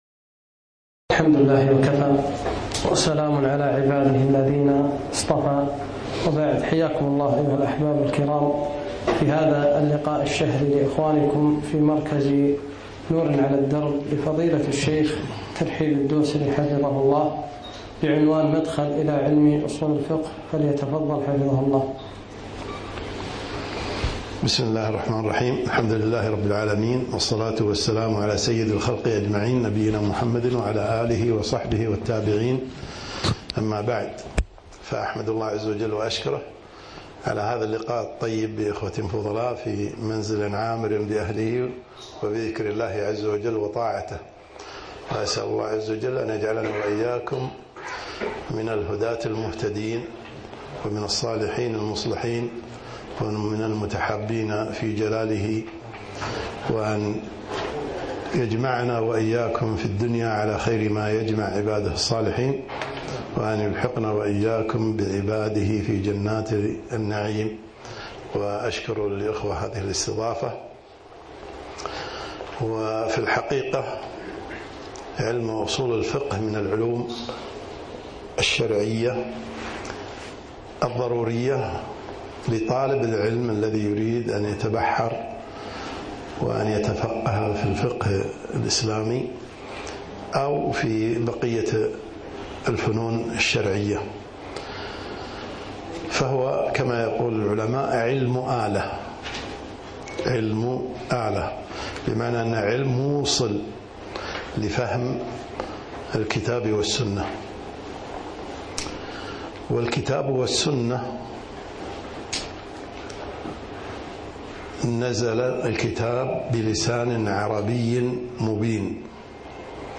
محاضرة - مدخل إلى علم أصول الفقه 1439هــ - دروس الكويت